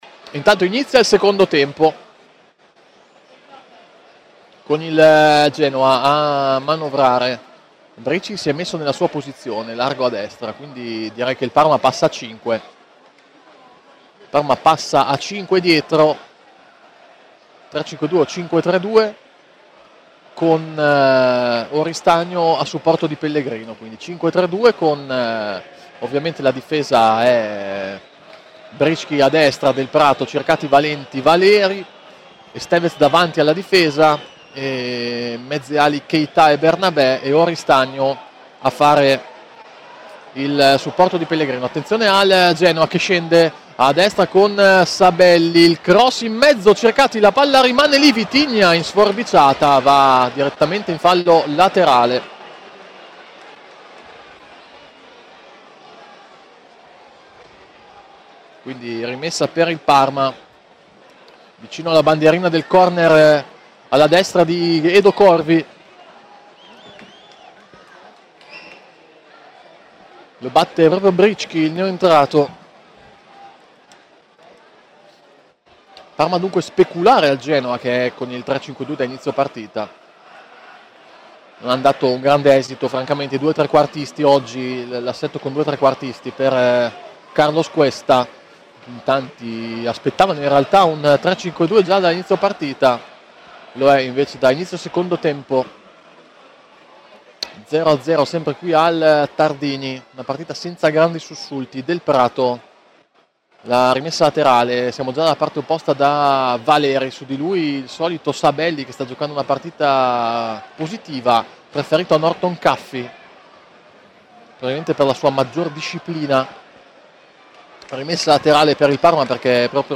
Radiocronache Parma Calcio Parma - Genoa 2° tempo - 18 gennaio 2026 Jan 18 2026 | 00:49:08 1x 00:00 / 00:49:08 Subscribe Share RSS Feed Share Link Embed